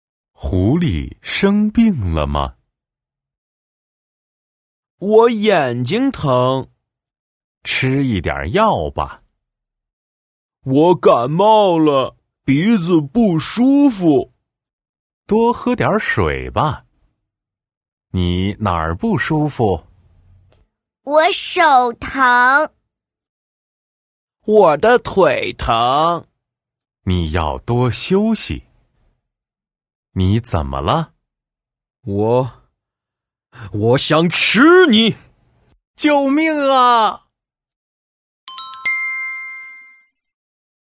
Đọc chuyện